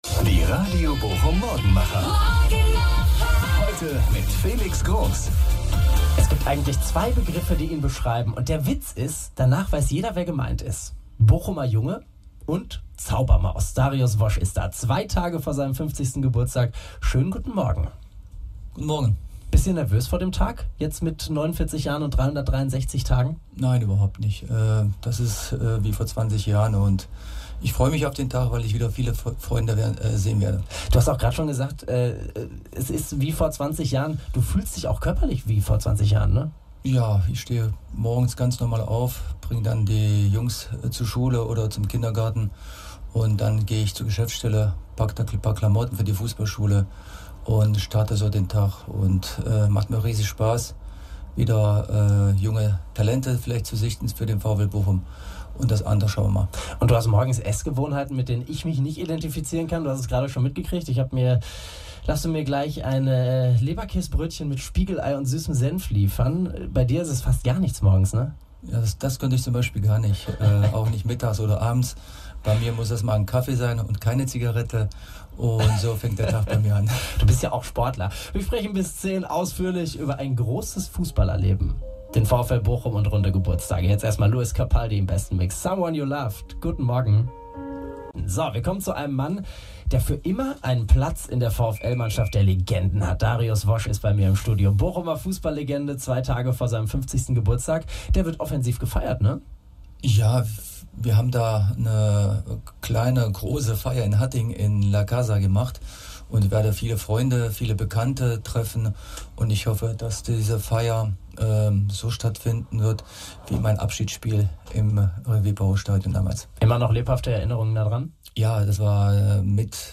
VfLer zu Gast im Radio Bochum-Studio.
Dariusz Wosz ist eine lebende VfL-Legende - und wurde am 8. Juni 50 Jahre alt! Anlässlich des runden Geburtstags haben wir ihn ins Radio Bochum-Studio geholt.